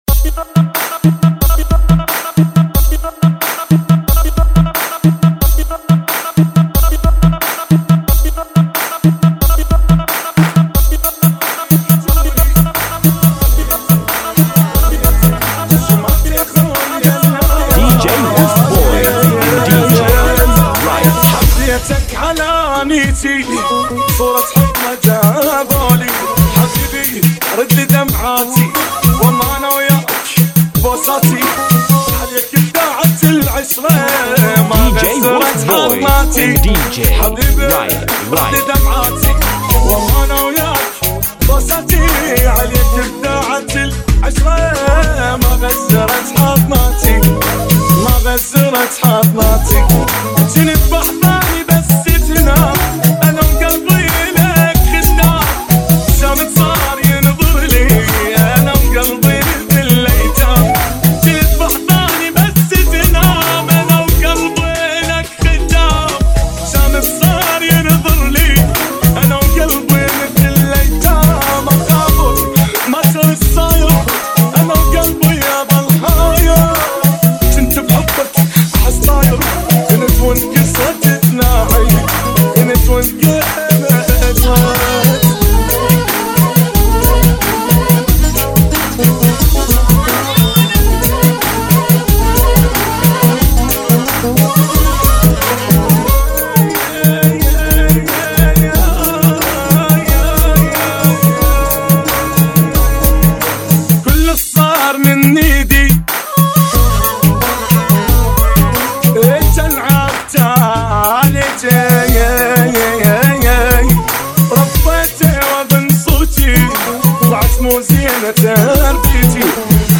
[ 90 Bpm ]